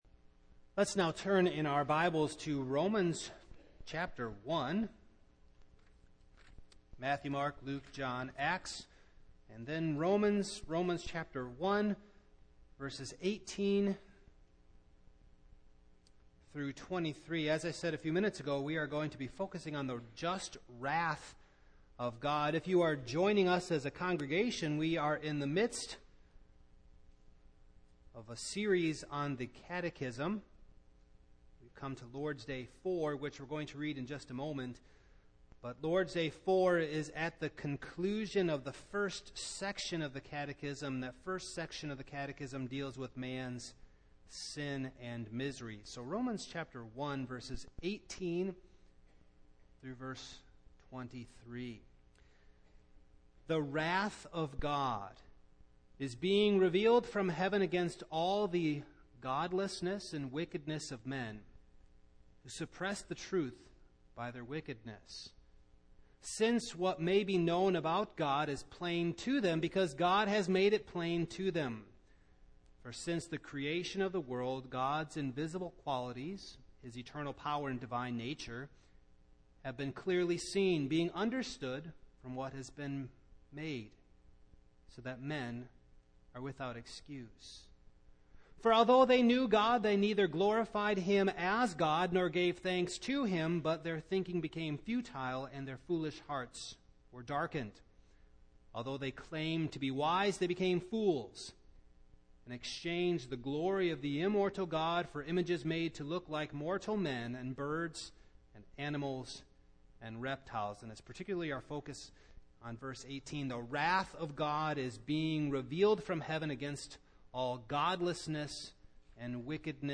Passage: Rom. 1:18-23 Service Type: Evening